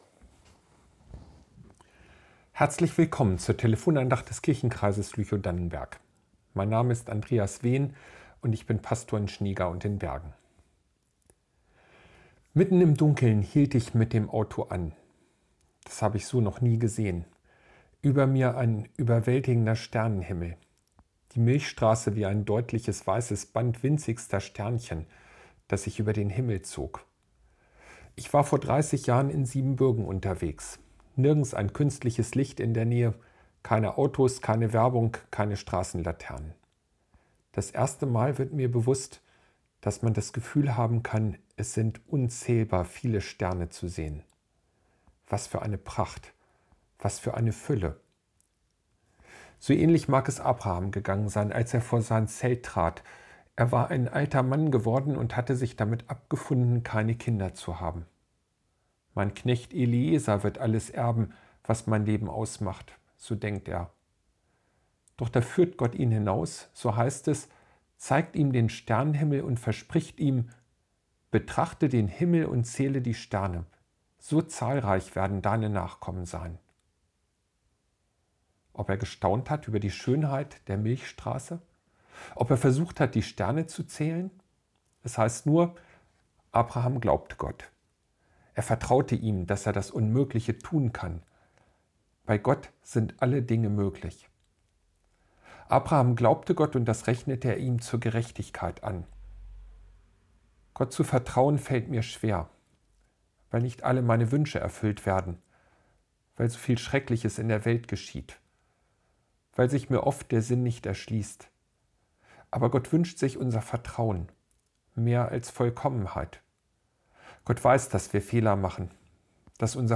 Betrachte den Himmel und zähle die Sterne ~ Telefon-Andachten des ev.-luth. Kirchenkreises Lüchow-Dannenberg Podcast